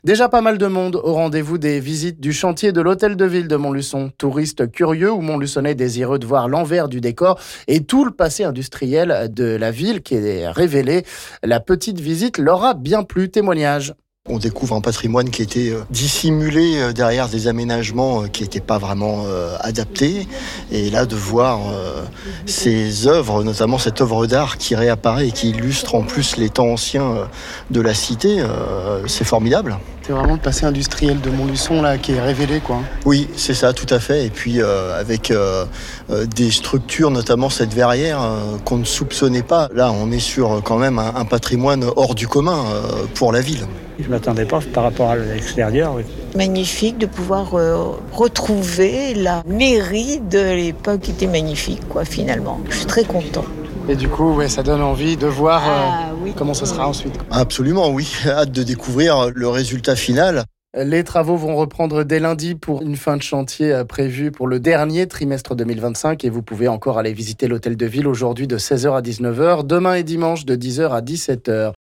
Entamées mercredi, ces visites ont déjà beaucoup plu comme en témoignent ces participants...